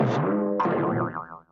CARTOON - CRASH 03
Category: Sound FX   Right: Both Personal and Commercial